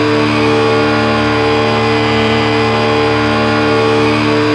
rr3-assets/files/.depot/audio/sfx/electric/mp4x_on_mid_9000rpm.wav
mp4x_on_mid_9000rpm.wav